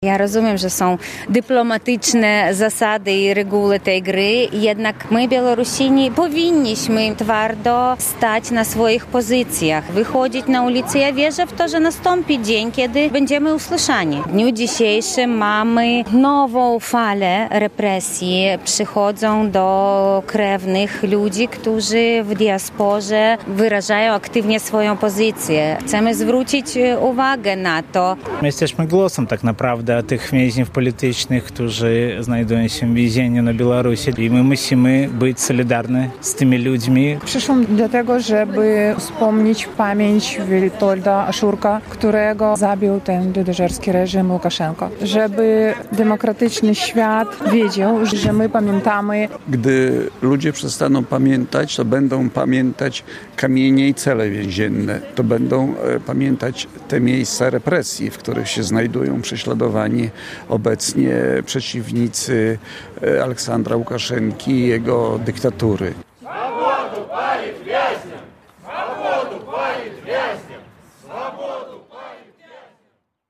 We wtorek (21.02) w „Dniu Więźniów Politycznych na Białorusi”, diaspora białoruska w Białymstoku zorganizowała wiec solidarności ze wszystkimi więźniami politycznymi w tym kraju.
Wiec solidarności z więźniami politycznymi na Białorusi w Białymstoku -relacja